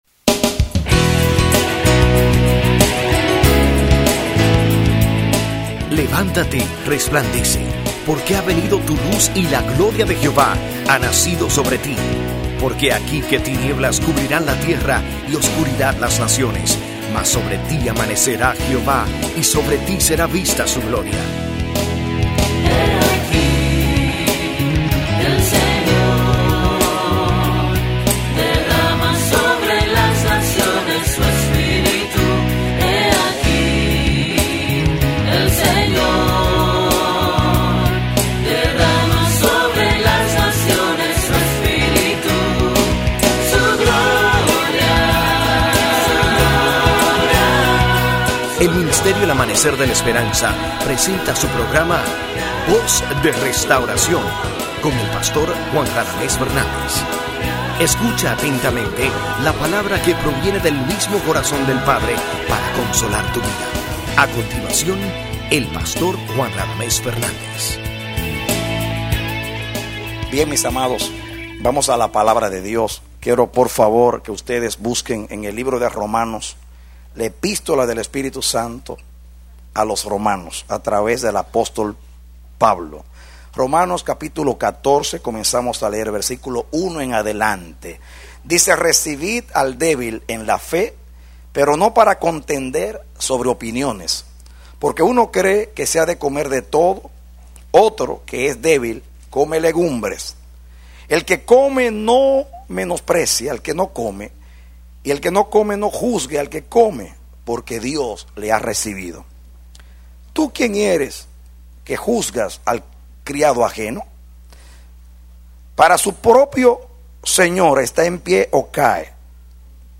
Predicado Diciembre 28, 2003